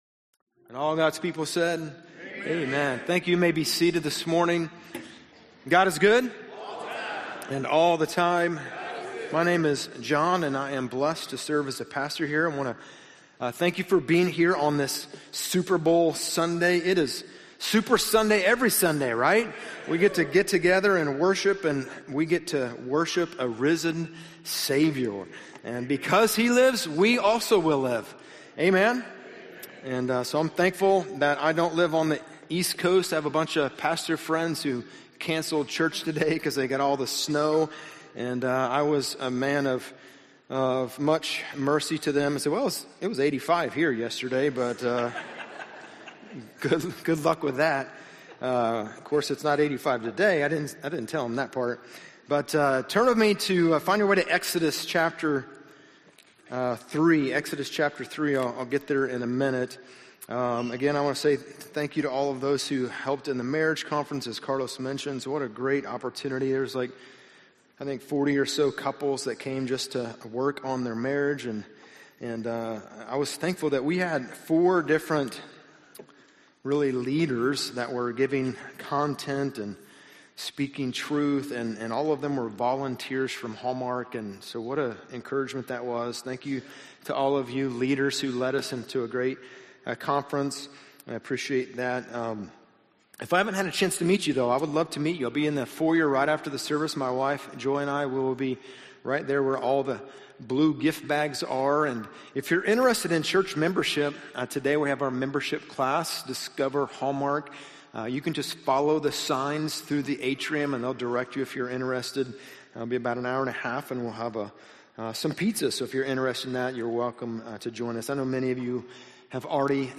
Beyond Me #2 - Moses - Sermons - Hallmark Church